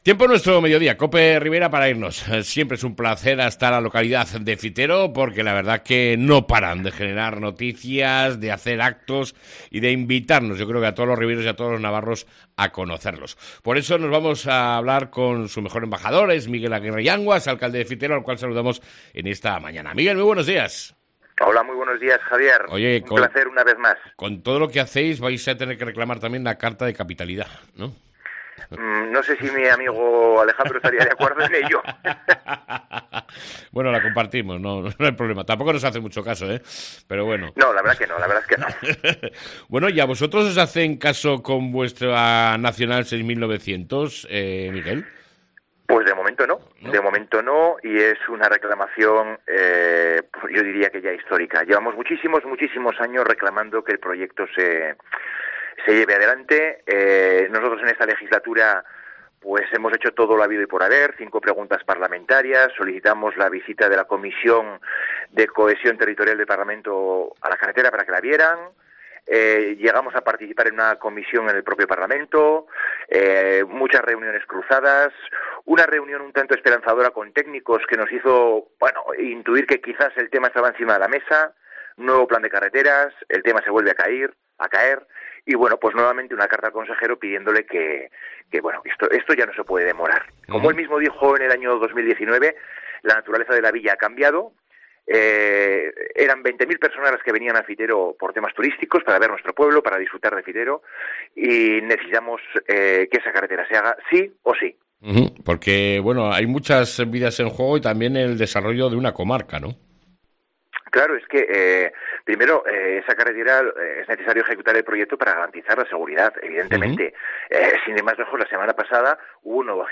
ENTREVISTA CON EL ALCALDE DE FITERO ,MIGUEL AGUIRRE YANGUAS